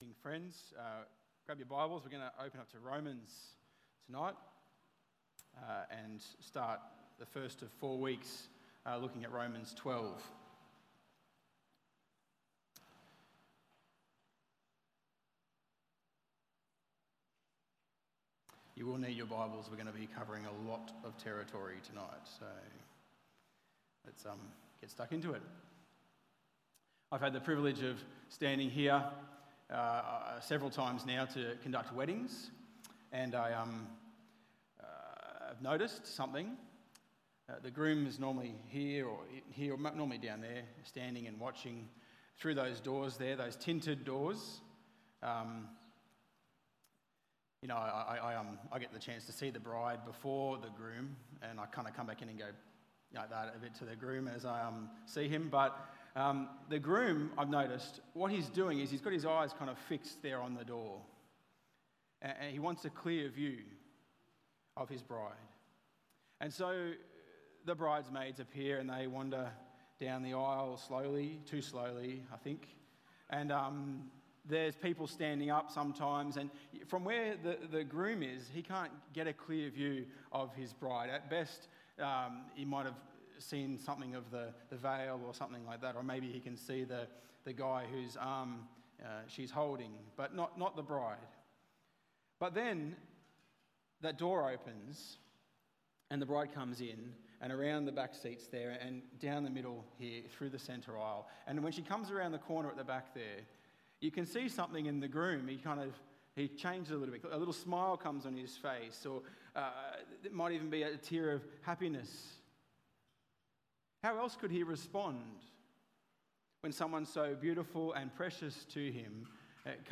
Tagged with Sunday Evening